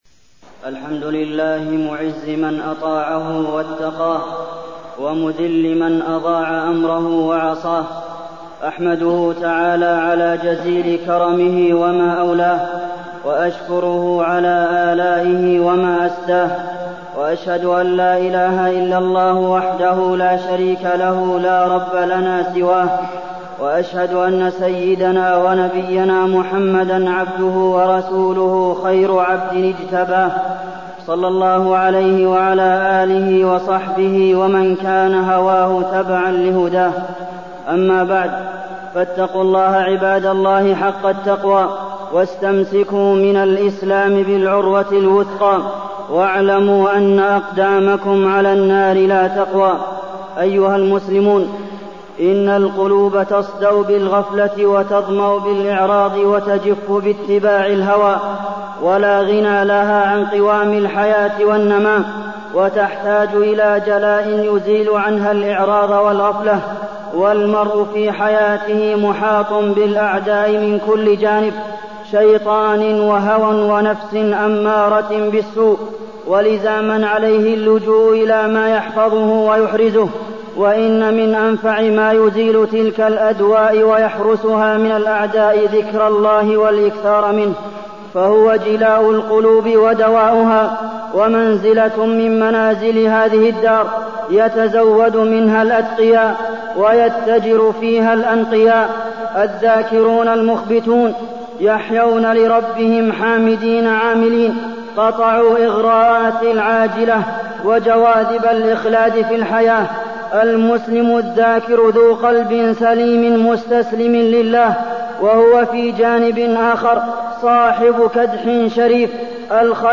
تاريخ النشر ١٣ رجب ١٤٢٠ هـ المكان: المسجد النبوي الشيخ: فضيلة الشيخ د. عبدالمحسن بن محمد القاسم فضيلة الشيخ د. عبدالمحسن بن محمد القاسم الذكر